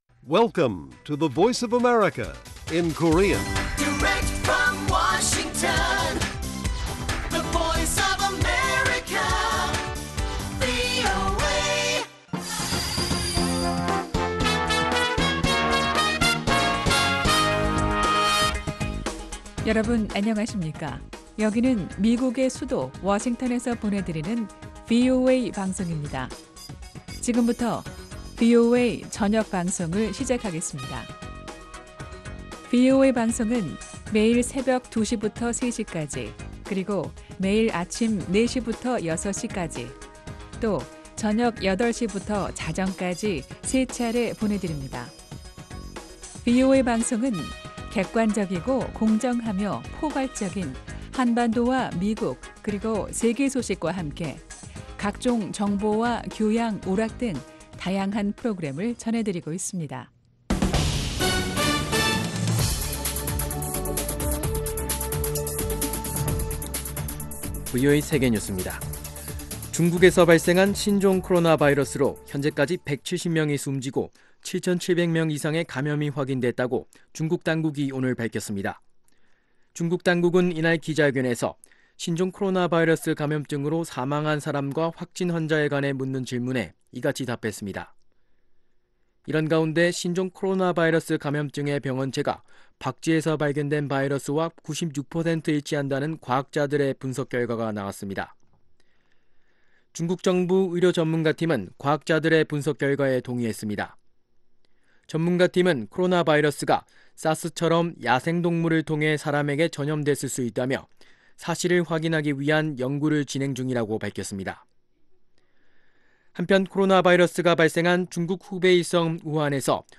VOA 한국어 간판 뉴스 프로그램 '뉴스 투데이', 2019년 1월 30일 1부 방송입니다. 남북한 당국이 신종 코로나바이러스 방역조치의 일환으로 개성 남북 연락사무소 운영을 잠정 중단했습니다. 전 세계로 확산하고 있는 신종 코로나바이러스가 미국 대북지원단체들의 방북 계획에도 영향을 미치고 있습니다.